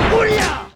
Fei's Battle Yell